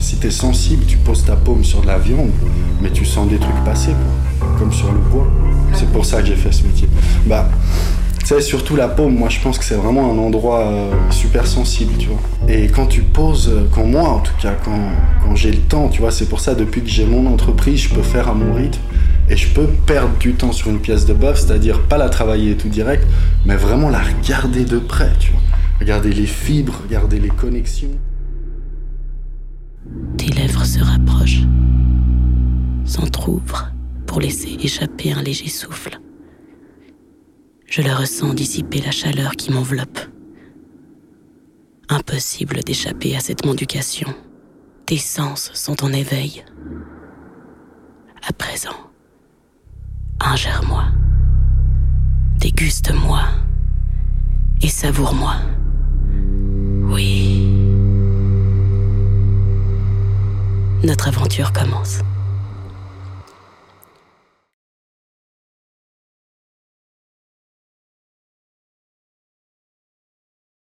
Das Hörstück Viande Sensible ist eine Erkundung von Fleisch, genauer Tierfleisch.